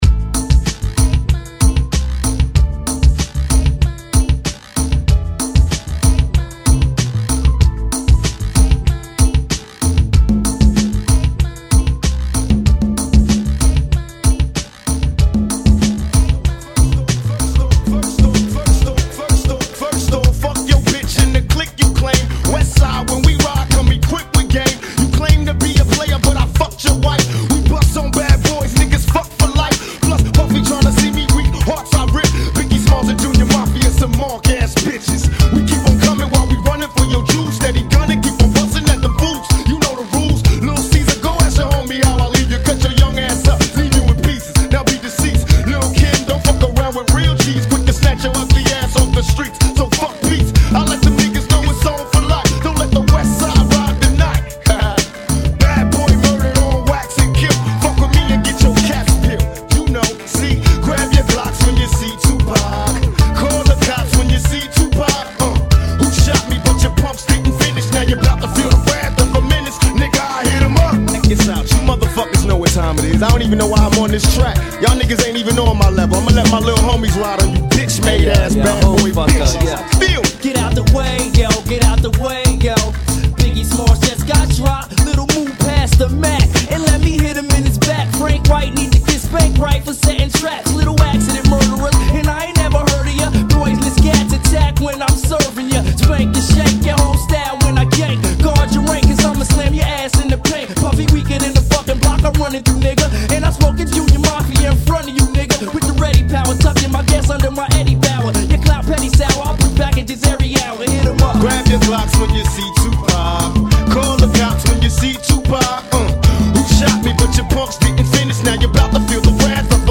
Funky [ 95 Bpm